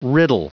Prononciation du mot riddle en anglais (fichier audio)
Prononciation du mot : riddle